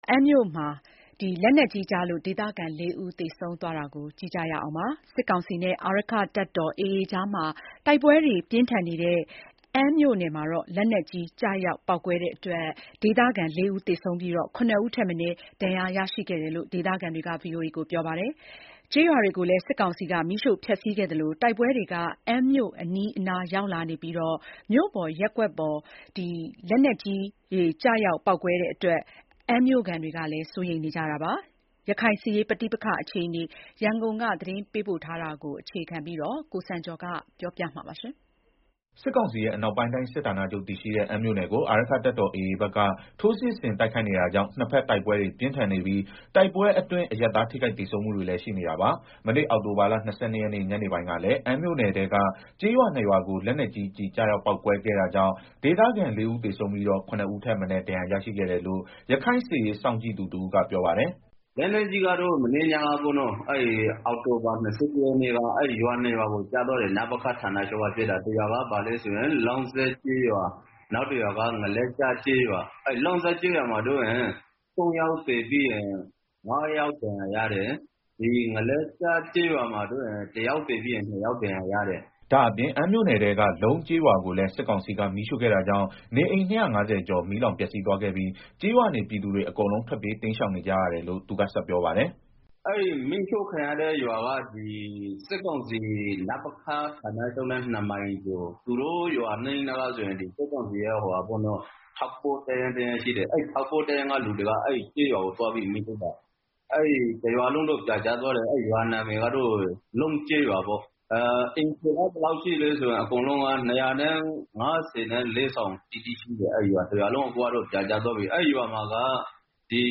စစ်ကောင်စီနဲ့ အာရက္ခတပ်တော်AAကြား တိုက်ပွဲတွေ ပြင်းထန်နေတဲ့ အမ်းမြို့နယ်မှာ လက်နက်ကြီးကျရောက်ပေါက်ကွဲမှုတွေကြောင့် ဒေသခံ ၄ဦး သေဆုံးပြီးတော့ ၇ ဦးထက်မနည်း ဒဏ်ရာရရှိခဲ့တယ်လို့ ဒေသခံတွေက ပြောပါတယ်။ ကျေးရွာတွေကိုလည်း စစ်ကောင်စီက မီးရှို့ဖျက်ဆီးခဲ့သလို တိုက်ပွဲတွေက အမ်းမြို့အနီးအနား ရောက်လာနေပြီး မြို့ပေါ်ရပ်ကွက်ပေါ် လက်နက်ကြီးကျည်တွေ ကျရောက်ပေါက်ကွဲလို့ အမ်းမြို့ခံတွေ စိုးရိမ်နေကြပါတယ်။ ရခိုင်စစ်ရေးပဋိပက္ခအခြေအနေ ရန်ကုန်က သတင်းပေးပို့ထားပါတယ်။